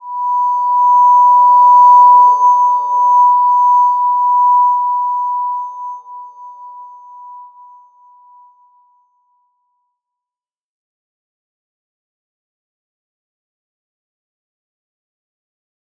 Slow-Distant-Chime-B5-mf.wav